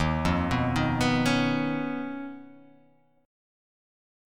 D#7sus2#5 Chord